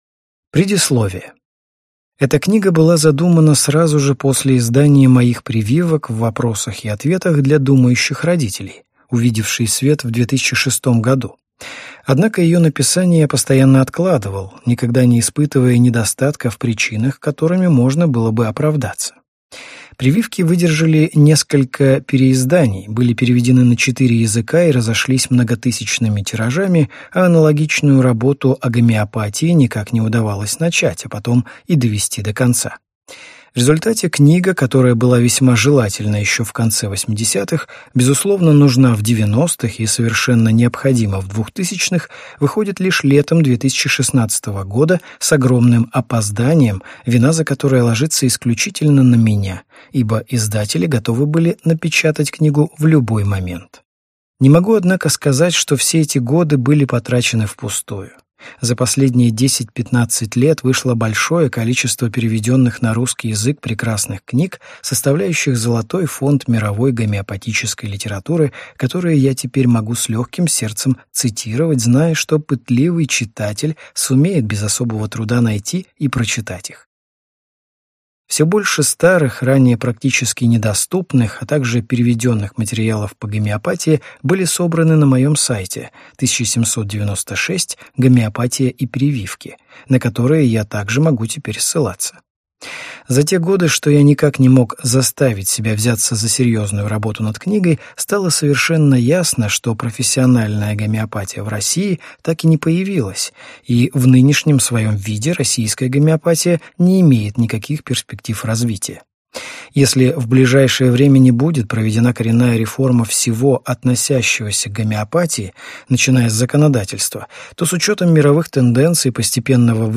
Аудиокнига Гомеопатия в вопросах и ответах | Библиотека аудиокниг